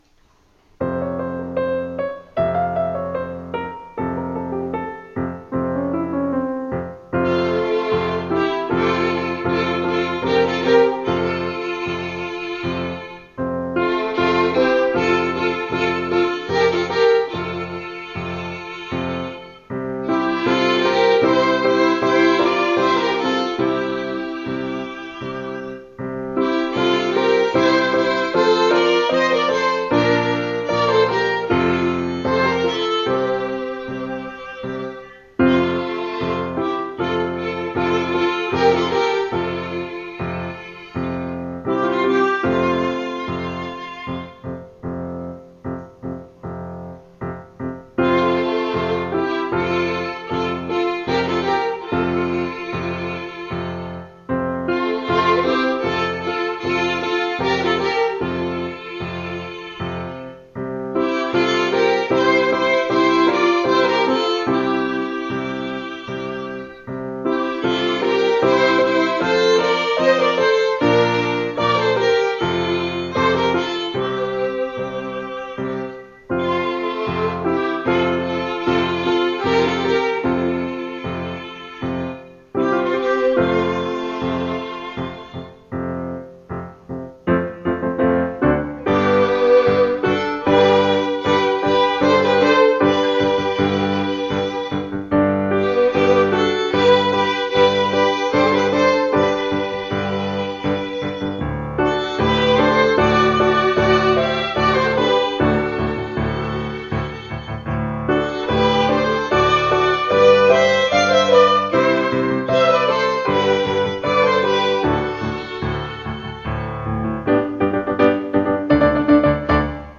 piano trio